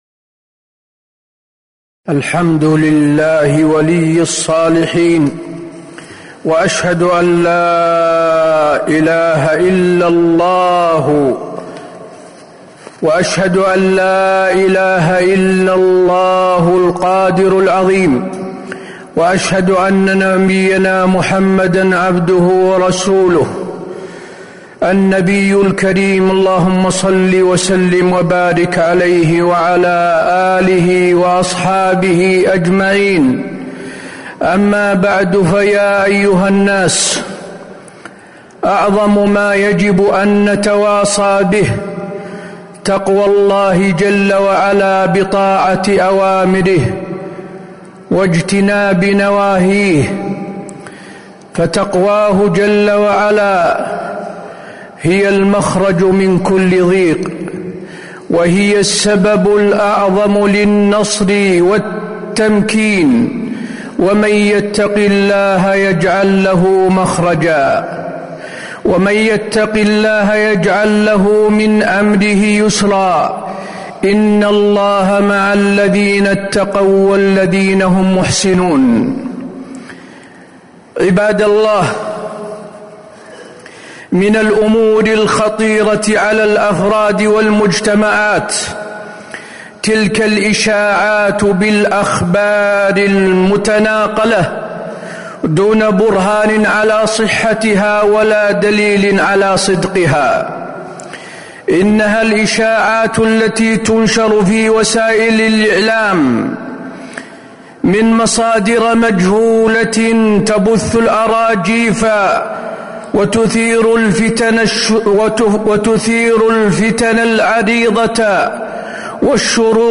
تاريخ النشر ٢٦ ربيع الثاني ١٤٤٥ هـ المكان: المسجد النبوي الشيخ: فضيلة الشيخ د. حسين بن عبدالعزيز آل الشيخ فضيلة الشيخ د. حسين بن عبدالعزيز آل الشيخ بيان خطورة الإشاعات